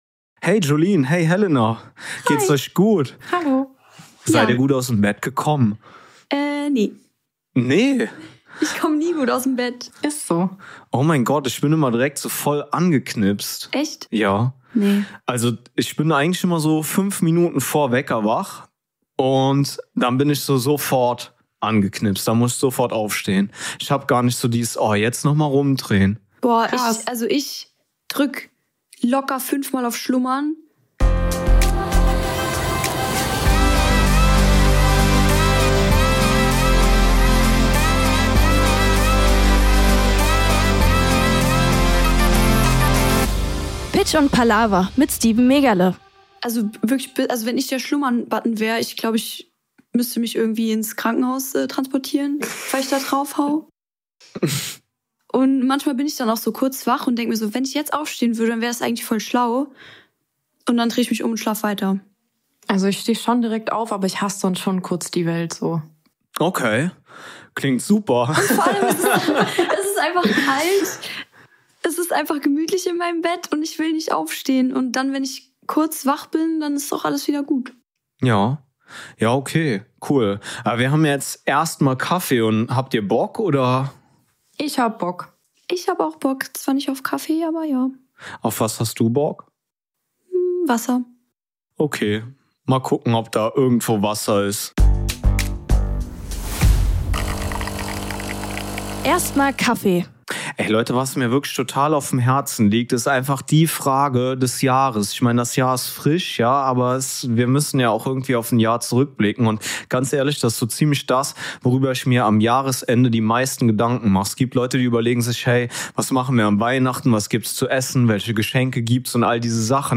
In der heutigen Folge hört ihr ein Dreiergespann, was unterschiedlicher kaum sein kann.